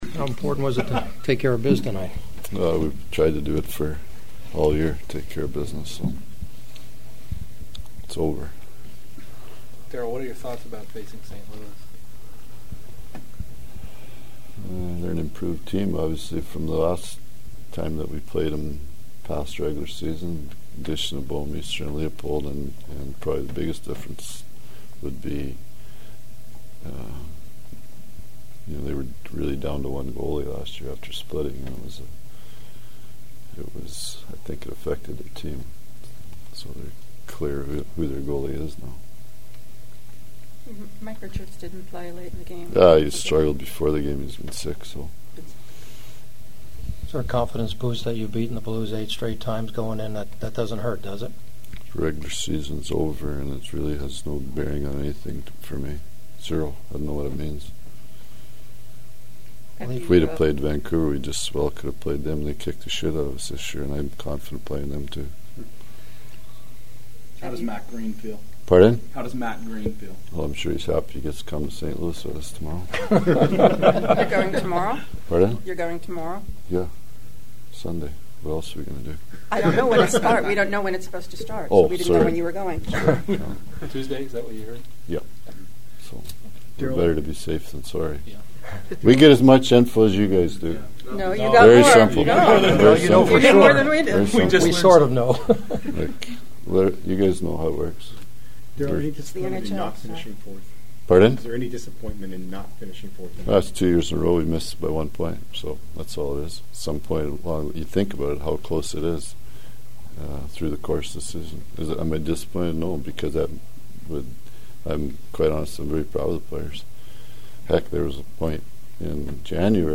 Head Coach Darryl Sutter doesn’t mince words during his postgame newsers and it was no different tonight no matter how hard I tried to get him to give me more than some coach-speak…although I really can’t blame him now to give us just the bare minimum as they approach another long ride to the finish line, but at least he did it with his usual dry sense of humor at times…